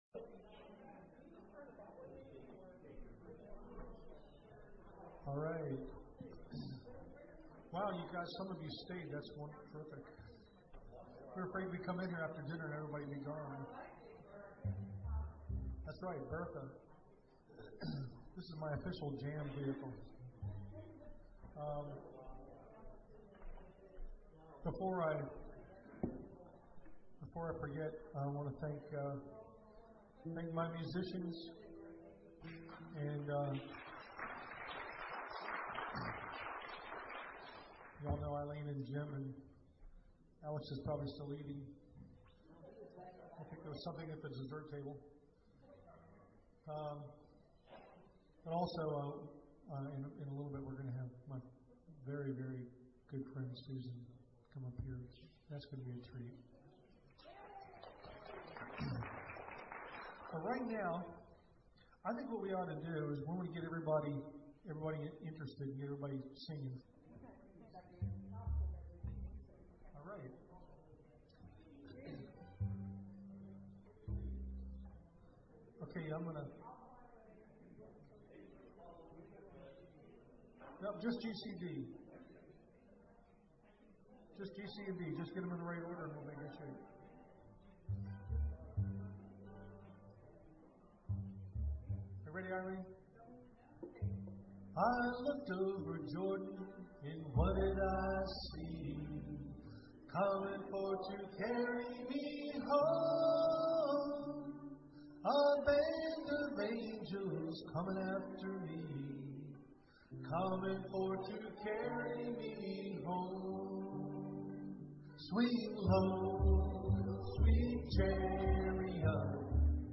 Gospel Jam - Clarksbury United Methodist Church
Gospel Jam